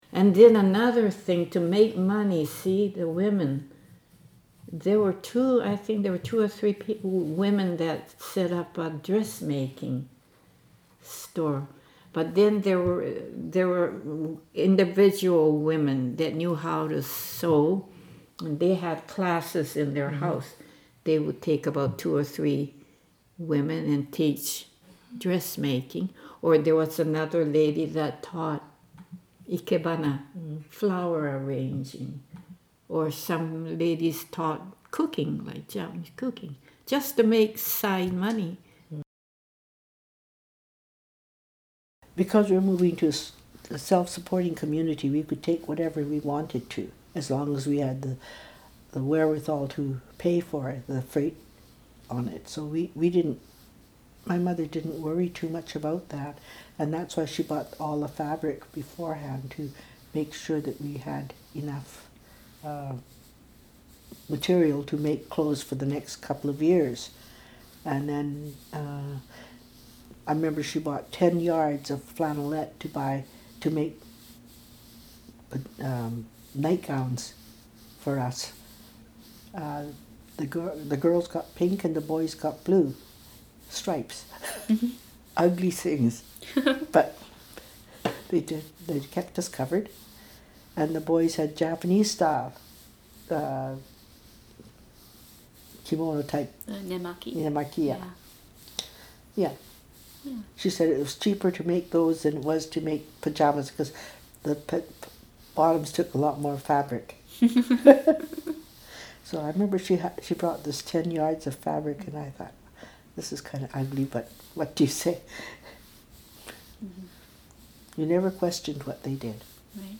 Oral Histories
Listen to stories told by Japanese Canadians relating to objects in the Journeys Education Kit.